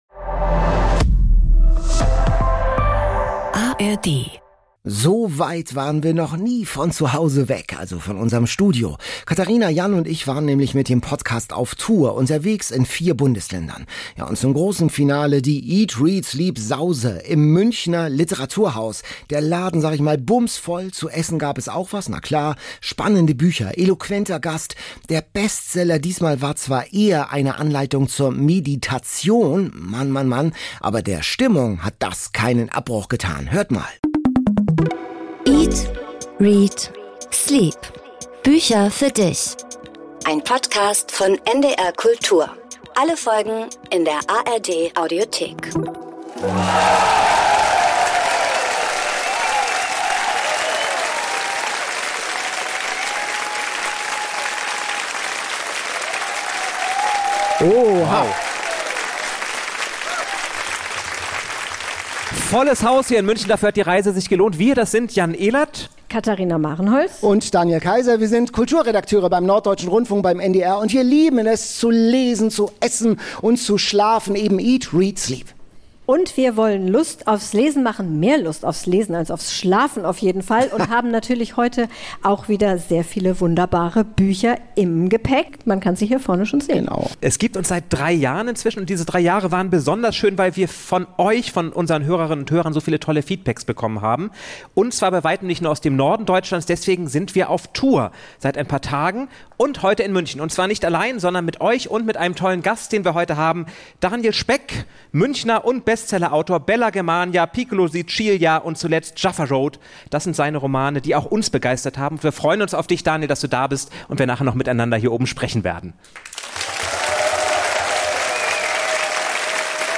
Vier Tage waren die Hosts auf Tour, mit 300 Fans feiern sie den Abschluss im Literaturhaus München - mit backenden Omas, Meditationslektüre und einem Bestsellerautor.